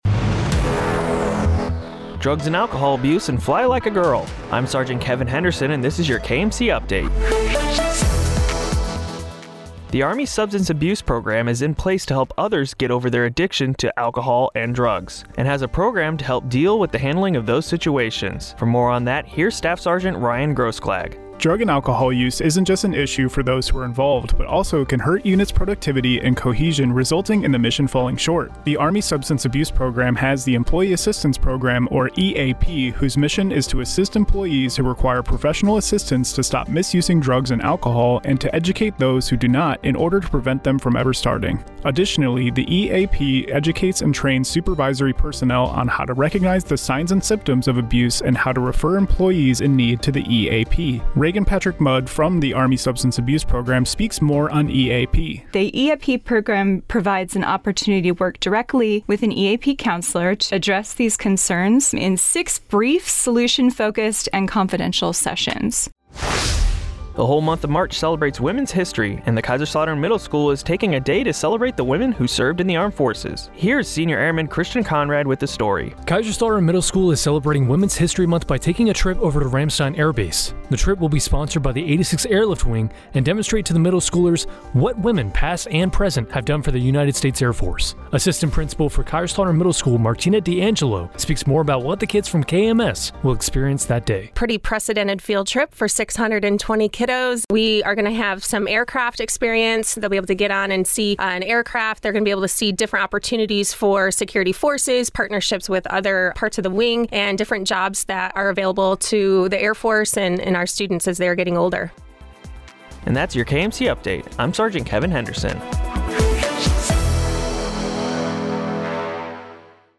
KMC News Update